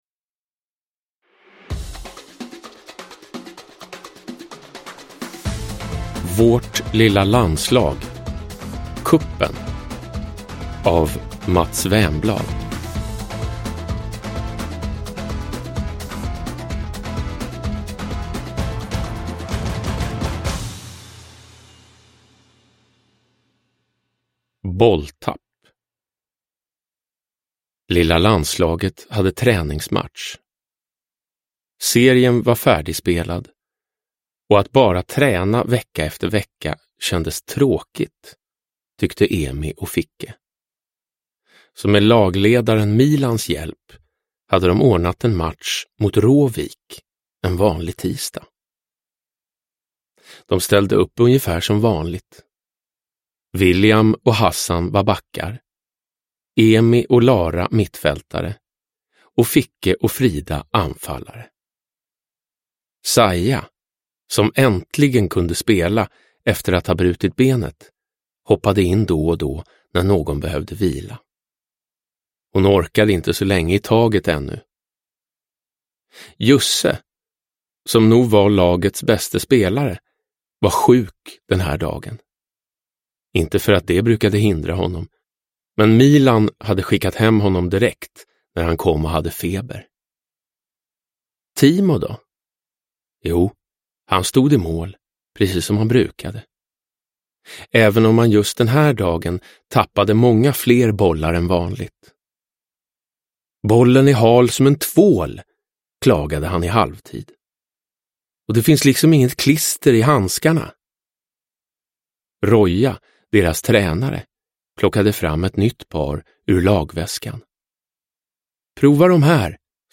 Cupen – Ljudbok – Laddas ner
Uppläsare: Jonas Karlsson